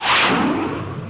missile.wav